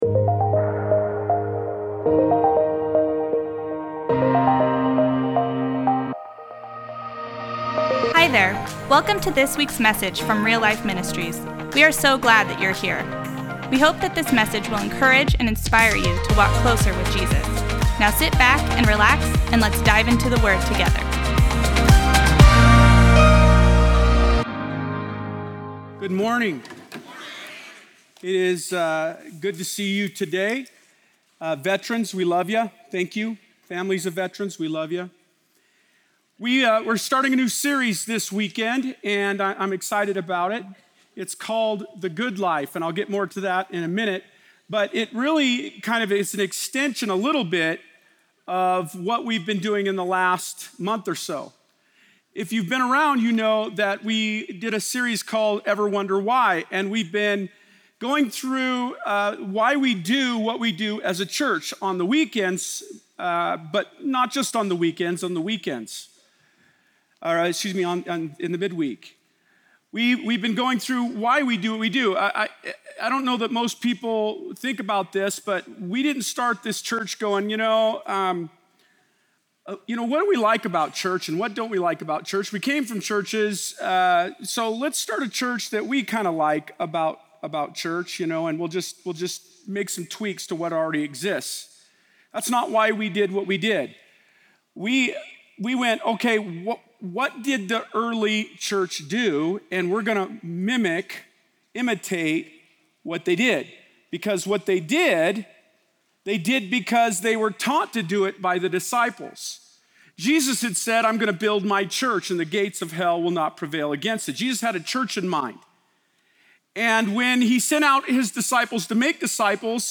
Post Falls Campus